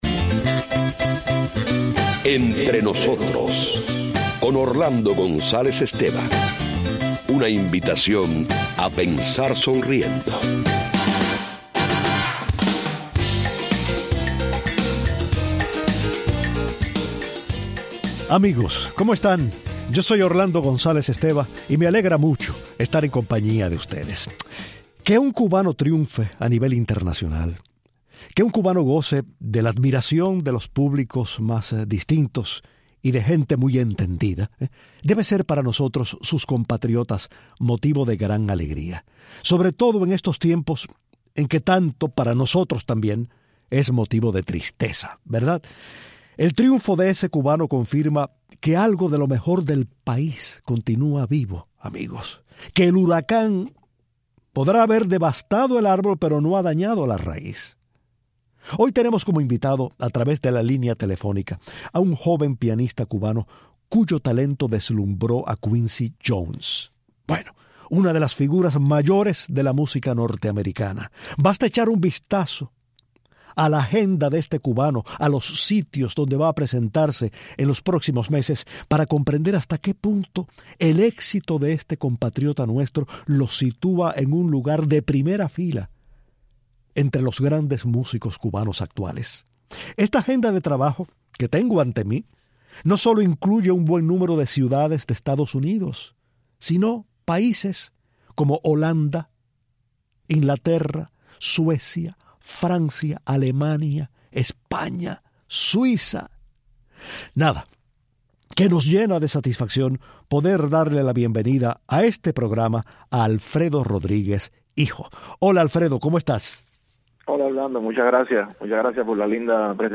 El admirable pianista cubano, cuyo talento lo ha convertido en una figura internacional, habla con inteligencia y desenvoltura de su espléndida carrera, sus padres, su relación libérrima y creadora con la música, y la omnipresencia de Cuba en sus presentaciones y discografía.